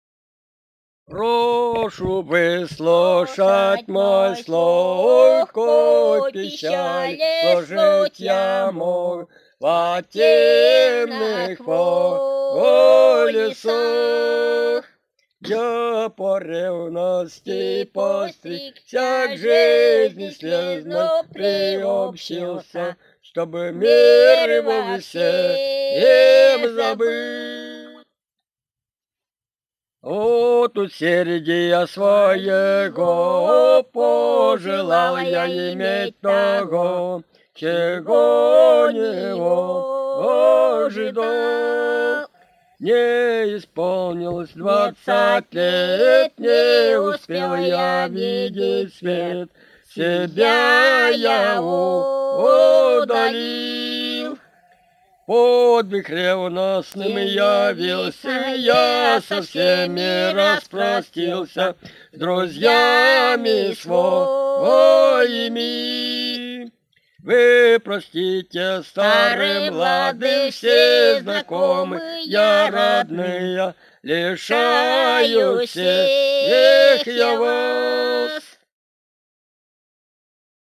Русские песни Алтайского Беловодья «Прошу выслушать мой слог», духовный стих.
с. Тихонька Усть-Коксинского р-на, Горно-Алтайская АО, Алтайский край